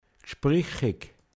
witzig, schlagfertig gspricheg